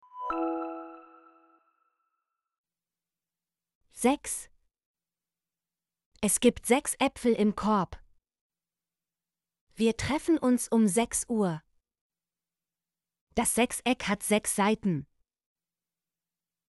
sechs - Example Sentences & Pronunciation, German Frequency List